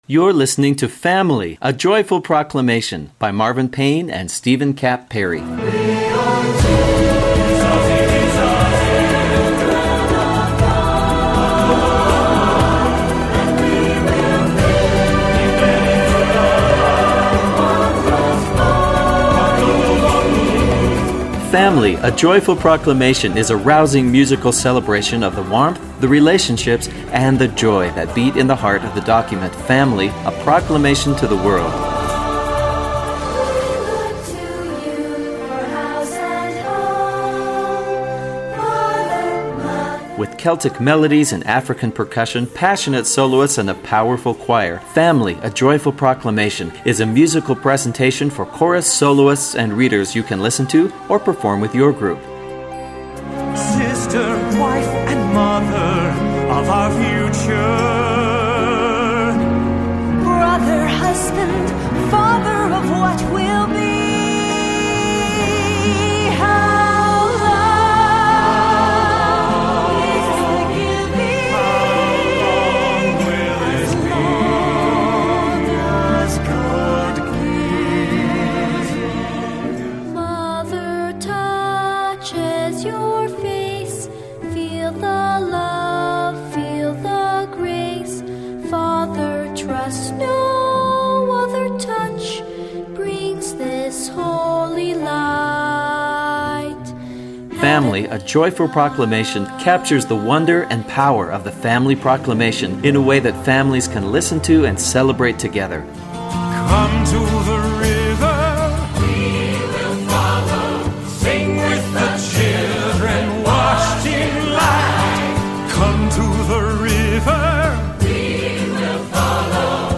the new world music celebration